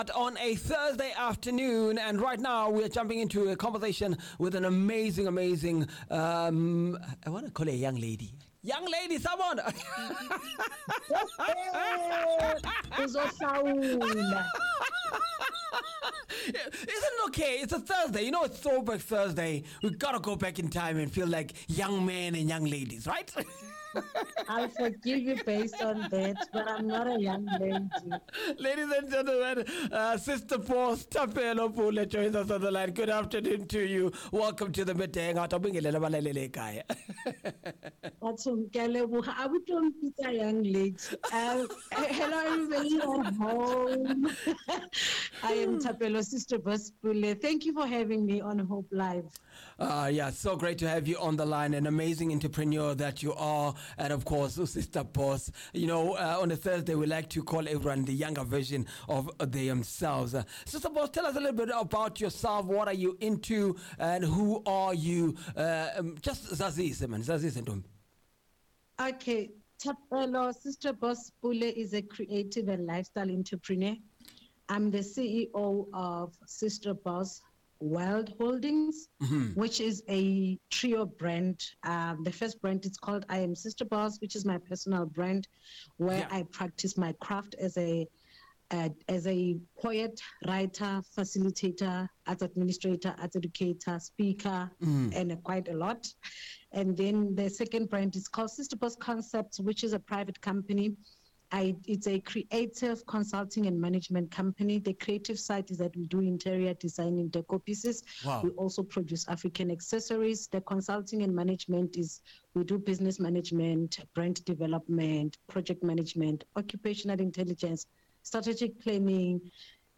Hope Alive interview.mp3